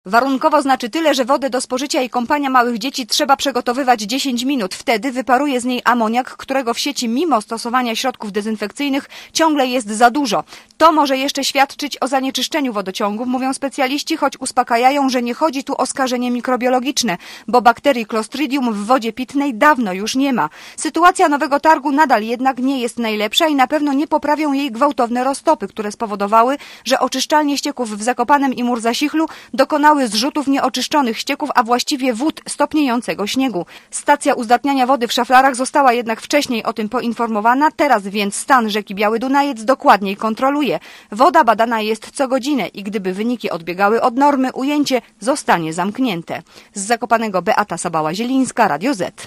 nowy_targ_-_woda.mp3